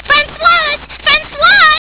Touch the pic or name to hear Azusa say it.